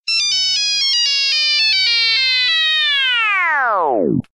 Nokia Funny Tone